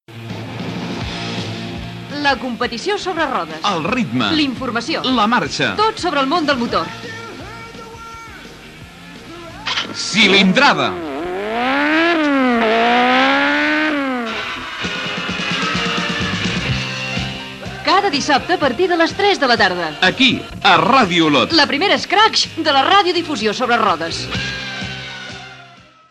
Promoció del programa Gènere radiofònic Esportiu